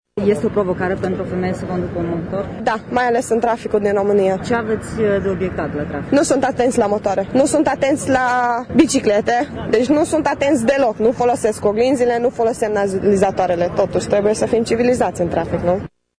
Şi doamnele şi domnişoarele pot conduce motociclete. O pasionată a cailor putere pe două roţi  crede că şoferii ar trebui să folosească mai des oglinda retrovizoare: